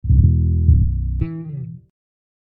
bs_Acoustic_Bass Size: 94,199 Smooth Jazz / Jazz / Bigband / Jamm'n. Samples: Pizz only, 3 velocity, slaps, hammerons, 1/2 slide up/down, vibrato, pulls, open slaps, long slides, effects.
=lowbass.mp3